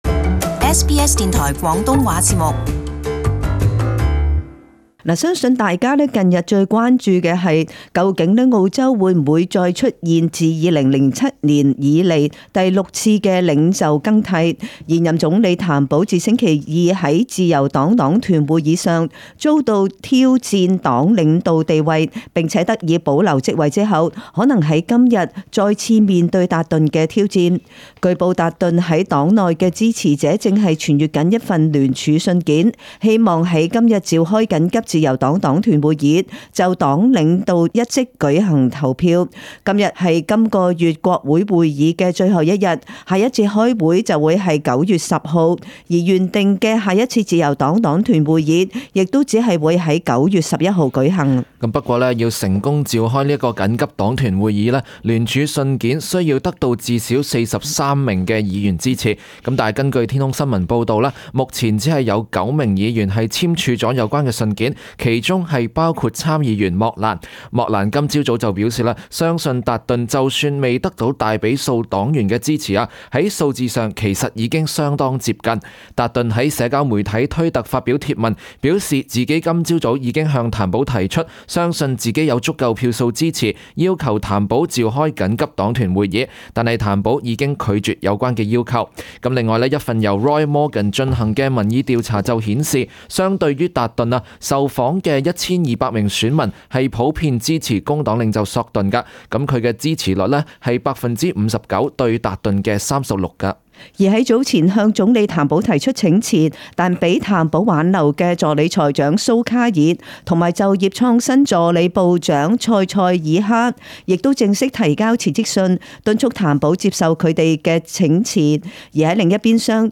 【時事報導】譚保領導地位或再受挑戰